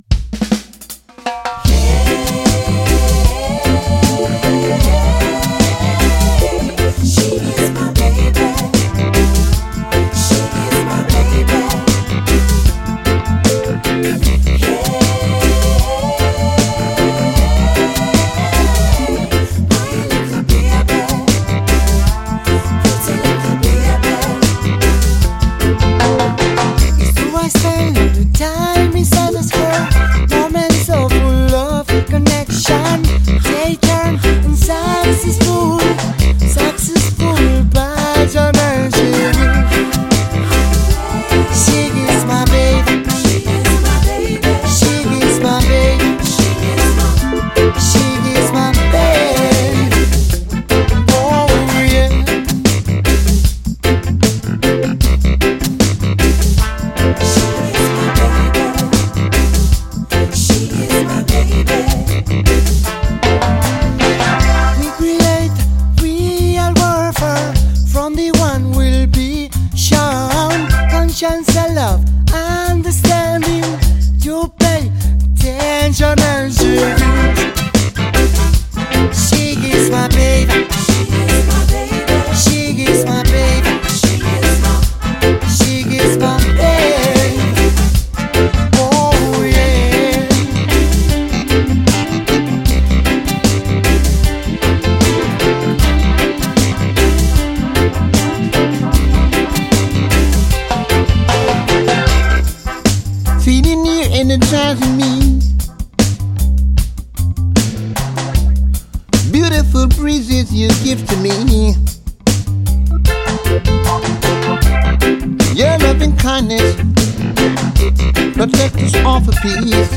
cantadas com sentimento puro